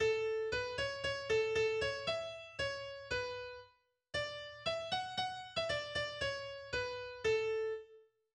Weihnachtslied